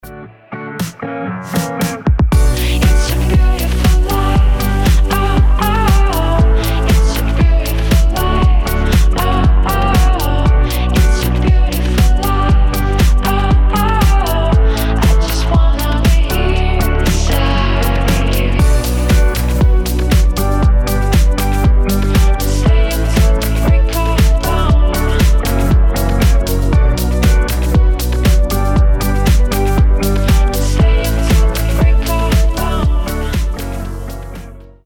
deep house
retromix
теплые
ремиксы